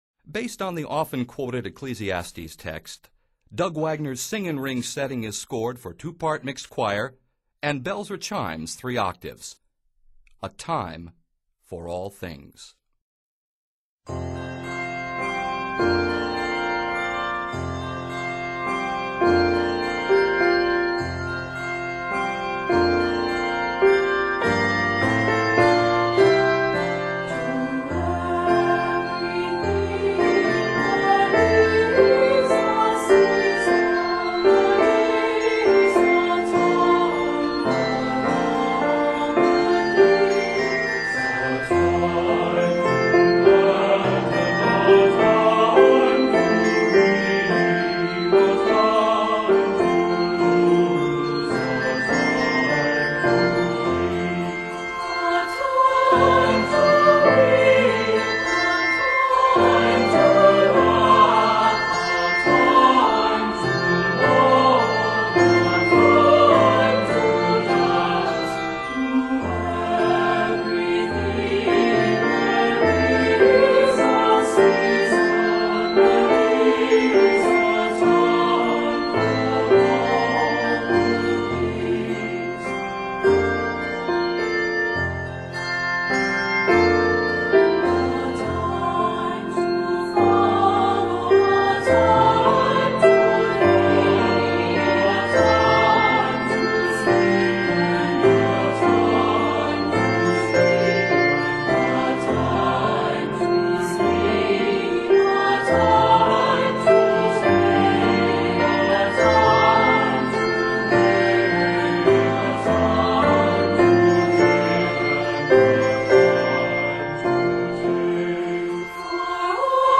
Set in F Major, this work is 93 measures.
Traditional Scottish Melody Arranger